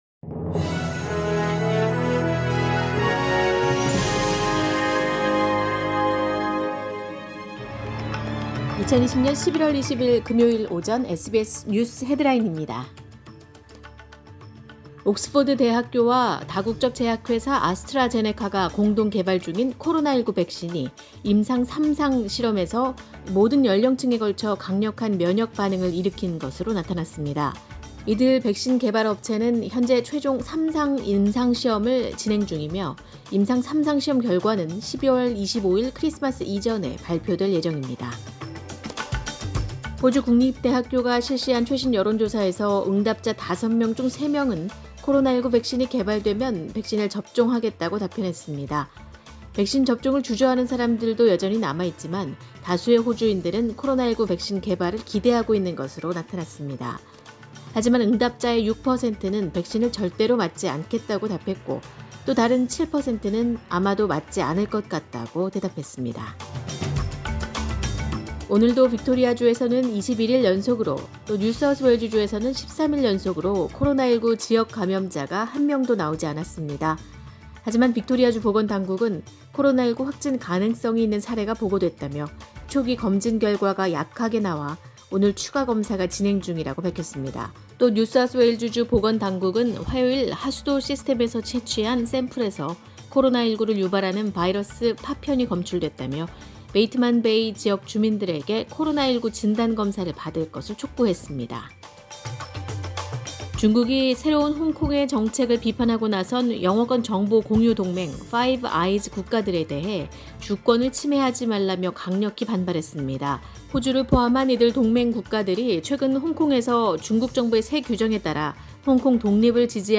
2020년 11월 20일 금요일 오전의 SBS 뉴스 헤드라인입니다.